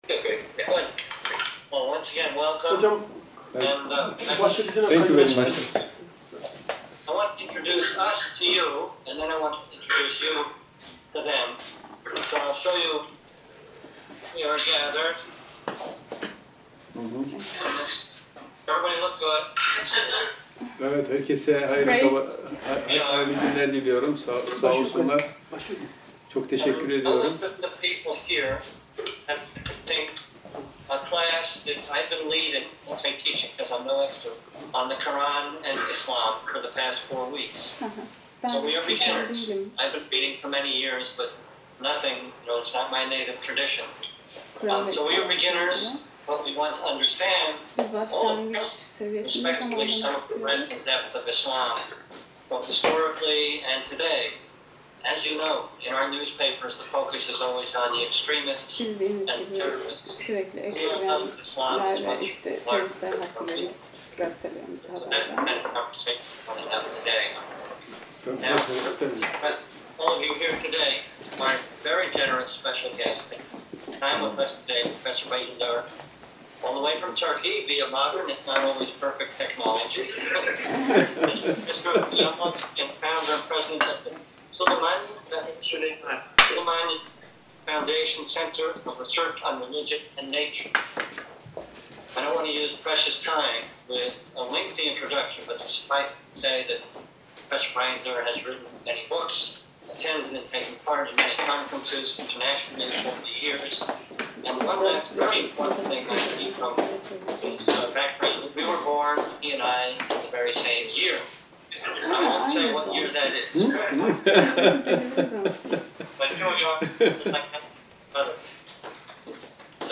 Süleymaniye Vakfı ile Boston Old Ship Kilisesi Arasında Soru-Cevap Oturumu
old-ship-kilisesinde-seminer.mp3